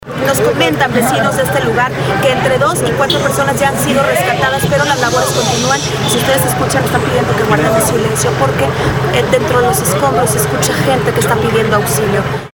Reportera de Telemundo cuenta sobre las labores de rescate en horas de la noche…
Terremoto-5-Piden-auxilio.mp3